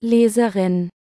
I do sound awesome, but that was a robot.